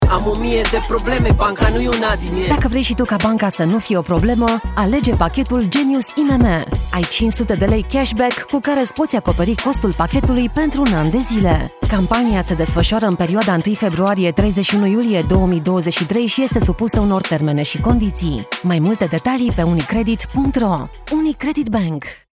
hell, fein, zart
Mittel plus (35-65)
Tutorial